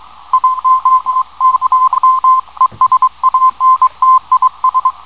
JA Multipath CW
A typical case of multipath for JA (Japan) stations heard here in the mid-atlantic.
ja_mp_cw.wav